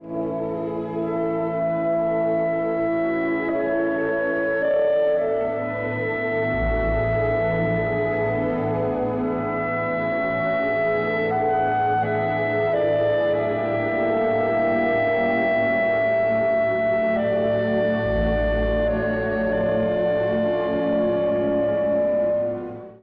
（Clの主題）
続くクラリネットの旋律は、聴く人すべての心に染みわたります。